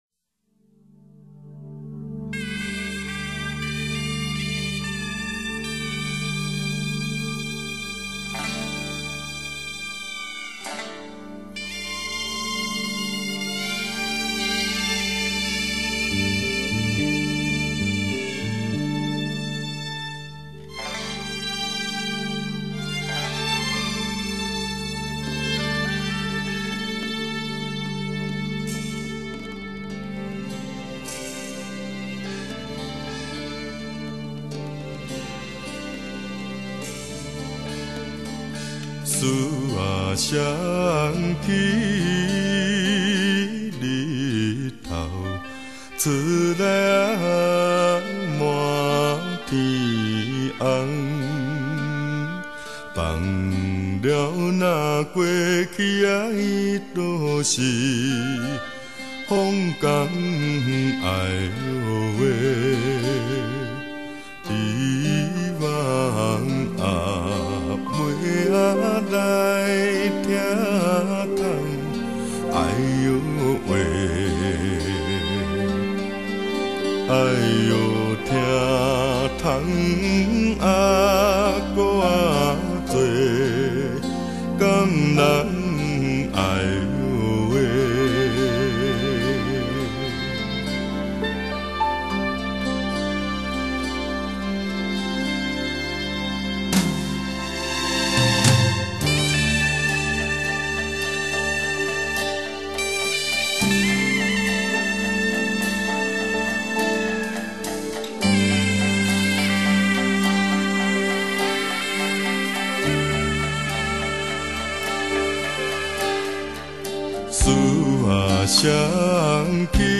第一首（其實應該說每一首）的腳踩大鼓與貝司同步合奏的低頻厚不厚？